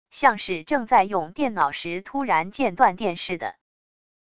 像是正在用电脑时突然间断电似的wav音频